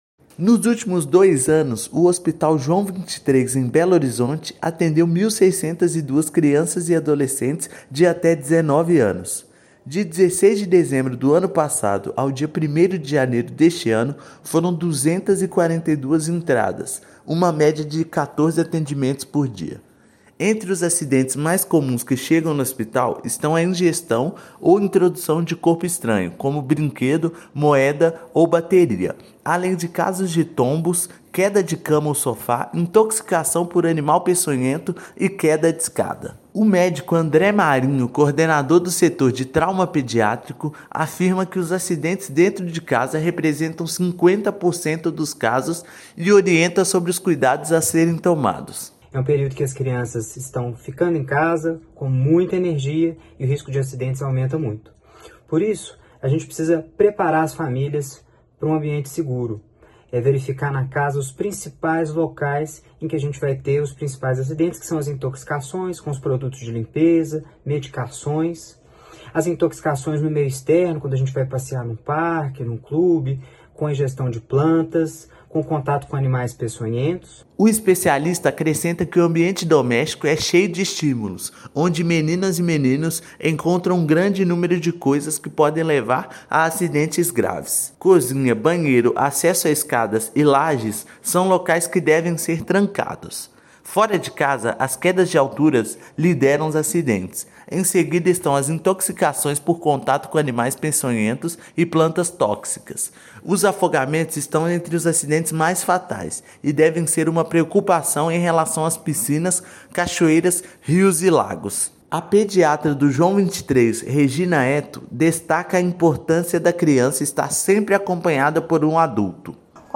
Maior pronto-socorro de Minas Gerais atende cerca de 800 crianças e adolescentes durante o período. Ouça matéria de rádio.